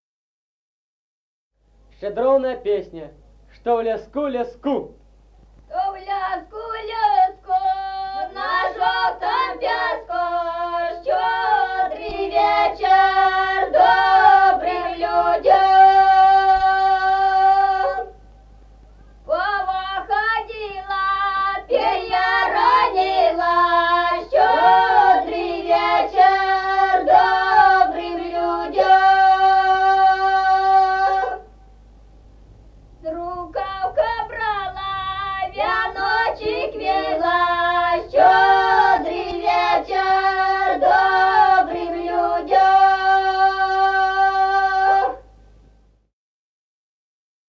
Народные песни Стародубского района «Что в леску, леску», новогодняя щедровная.
1953 г., с. Мишковка.